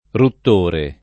ruttore [ rutt 1 re ]